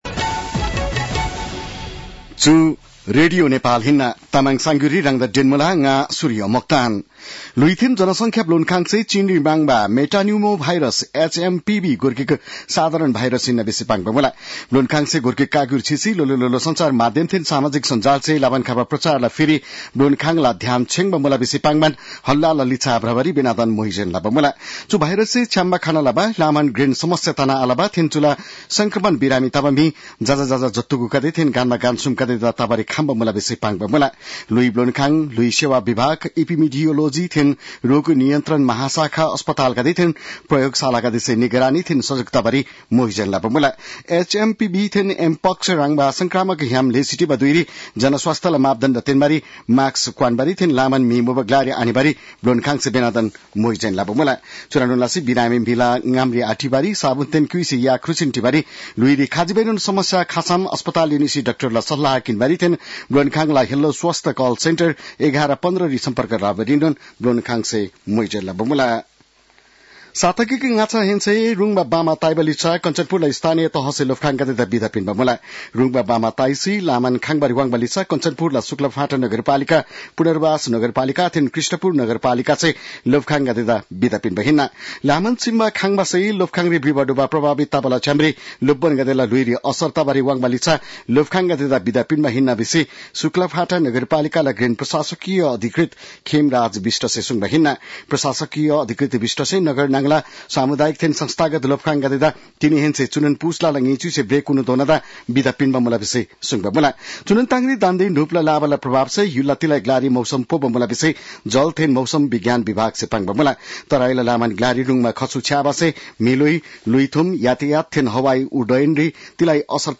तामाङ भाषाको समाचार : २६ पुष , २०८१
Tamang-news-9-25.mp3